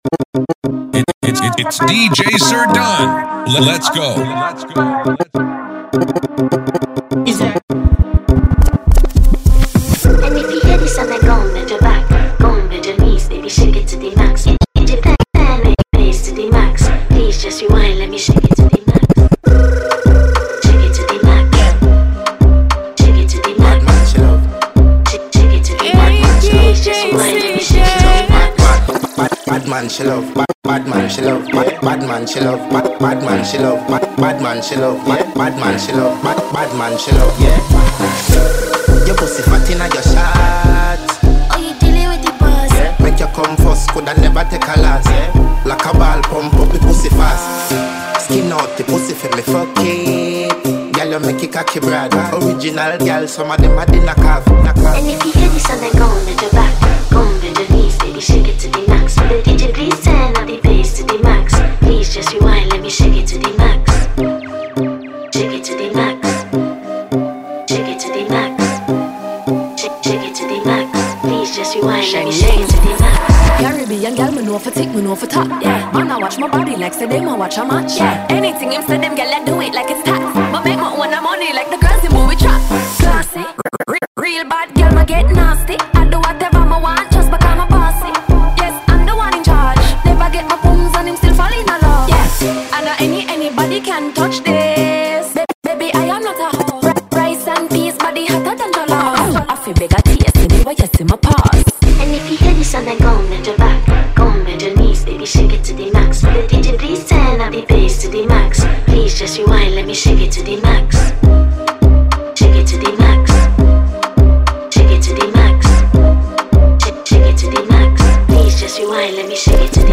Packed with banging riddims and crowd-moving anthems